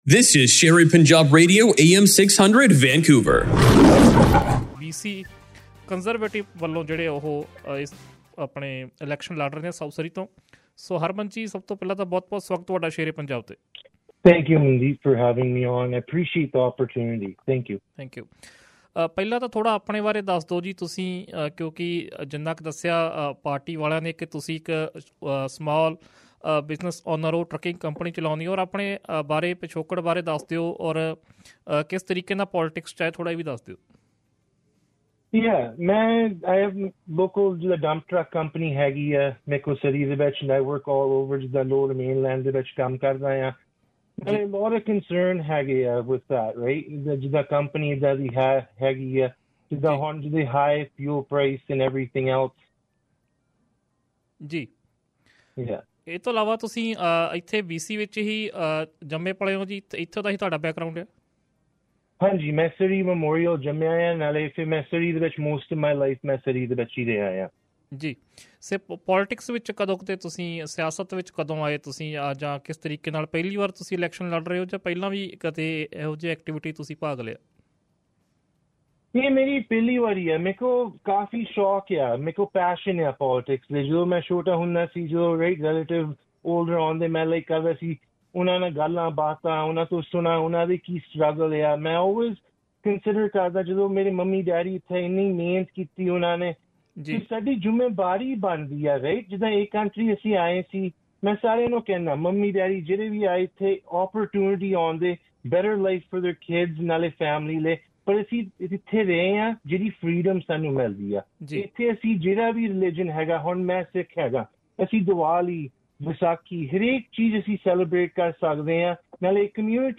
Exclusive Interview